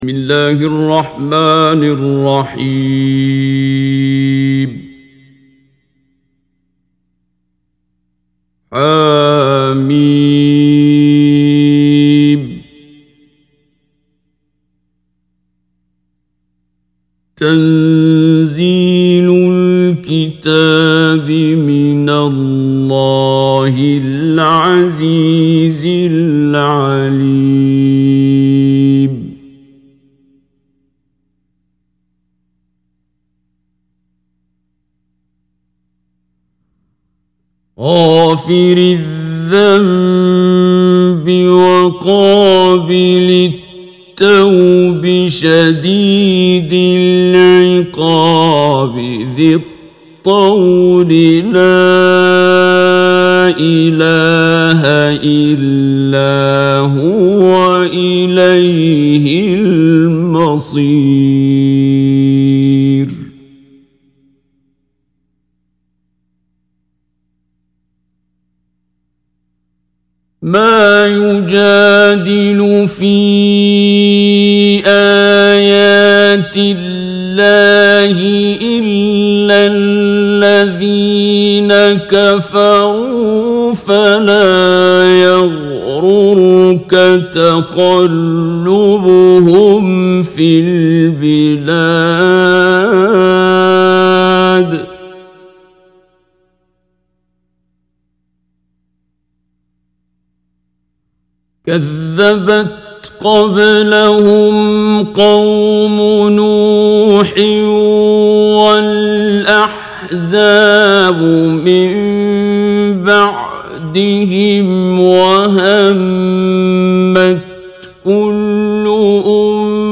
تحميل سورة غافر mp3 بصوت عبد الباسط عبد الصمد مجود برواية حفص عن عاصم, تحميل استماع القرآن الكريم على الجوال mp3 كاملا بروابط مباشرة وسريعة
تحميل سورة غافر عبد الباسط عبد الصمد مجود